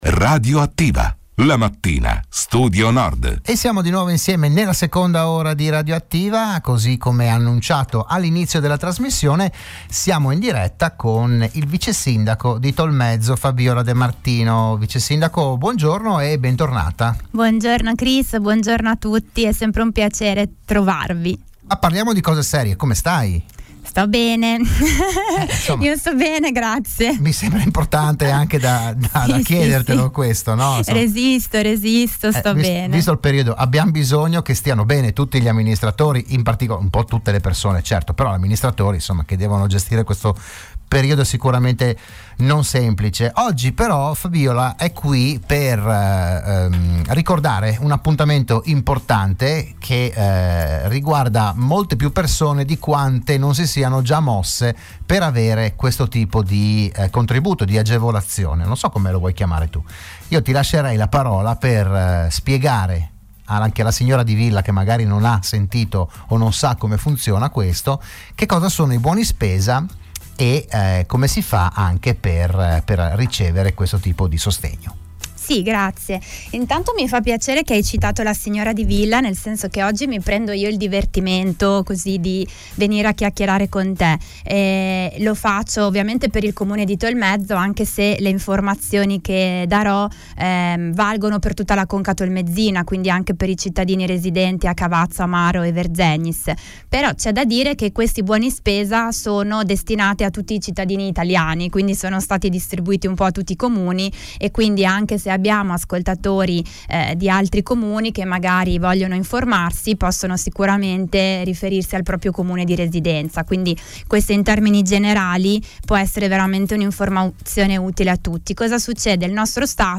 L'AUDIO e il VIDEO dell'intervento del vicesindaco di Tolmezzo a "RadioAttiva", la trasmissione del mattino di Radio Studio Nord